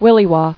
[wil·li·waw]